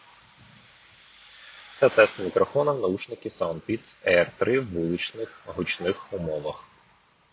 Мікрофон:
В гучних умовах: